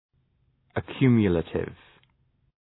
Προφορά
{ə’kju:mjə,leıtıv}